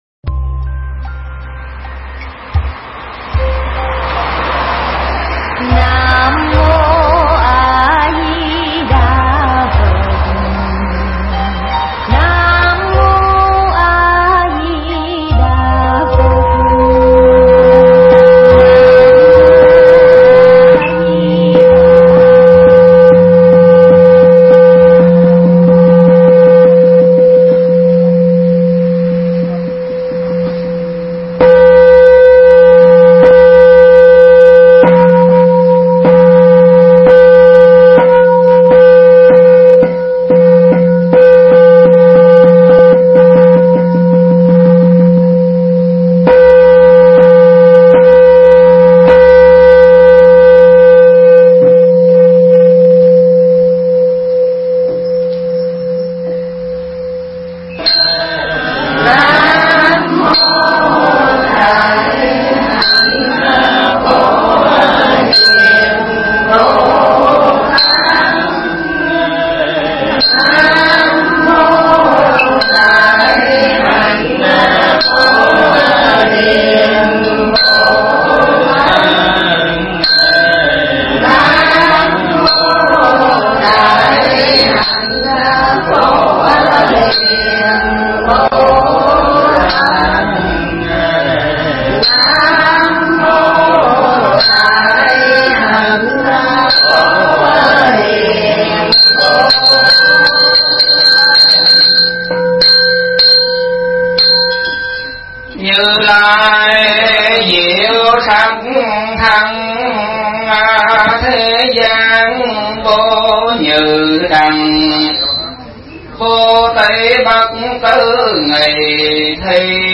Nghe Mp3 thuyết pháp Công Đức Pháp Bố Thí